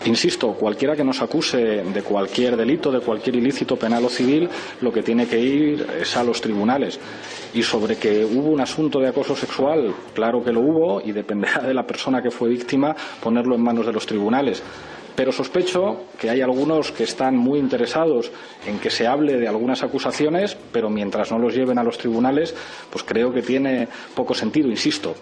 Declaraciones de Pablo Iglesias